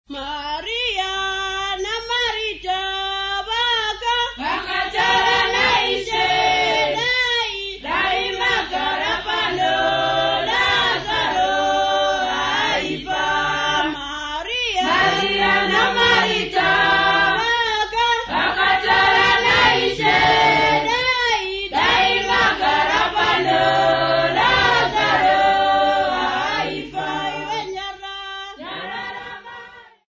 Kwanongoma College of Music participants
Folk music
Sacred music
Field recordings
Africa Zimbabwe Bulawayo rh
Unaccompanied religious song.
7.5 inch reel